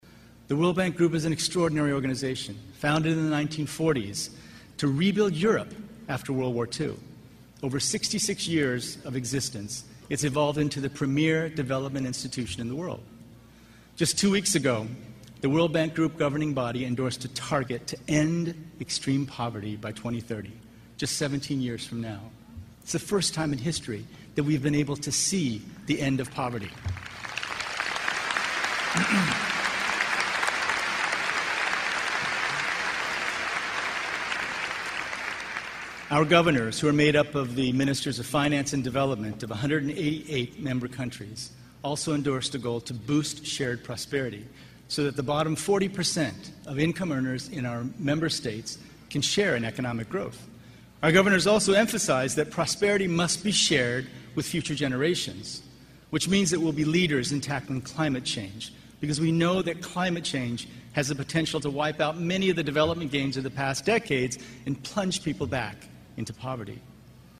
公众人物毕业演讲 第72期:金墉美国东北大学(11) 听力文件下载—在线英语听力室